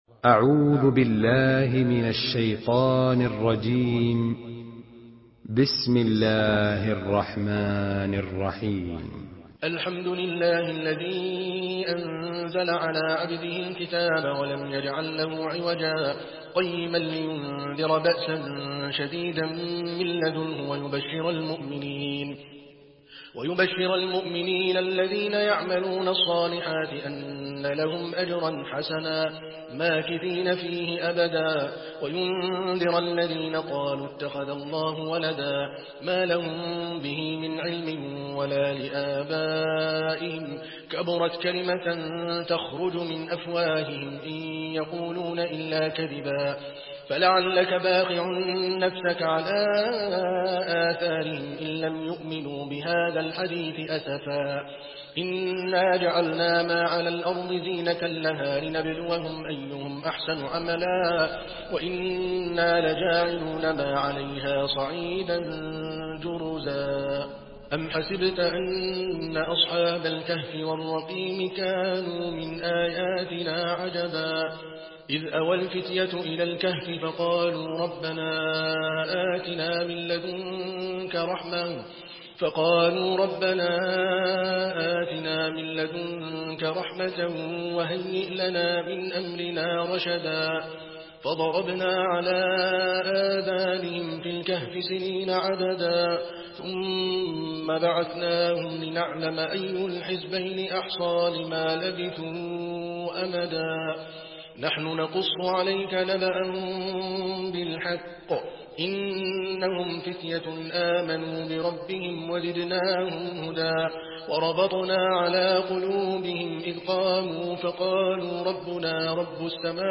Surah Al-Kahf MP3 in the Voice of Adel Al Kalbani in Hafs Narration
Surah Al-Kahf MP3 by Adel Al Kalbani in Hafs An Asim narration.